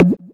squeeze.wav